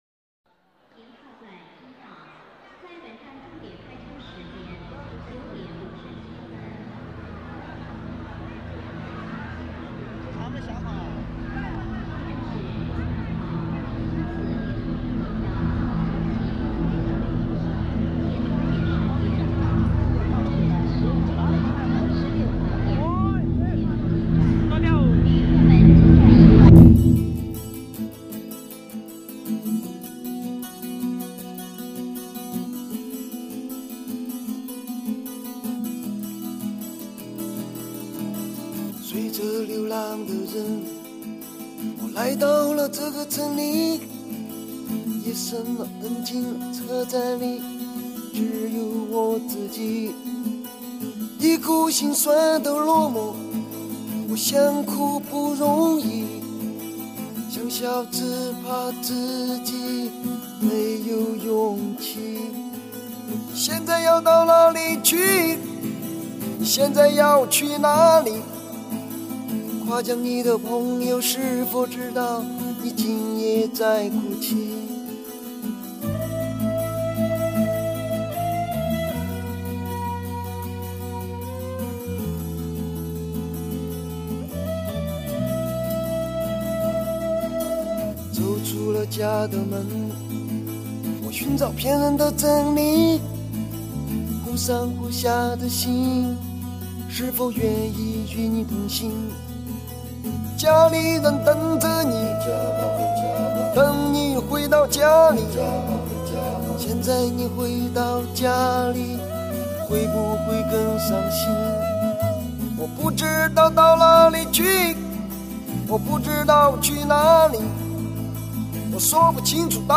风格：英伦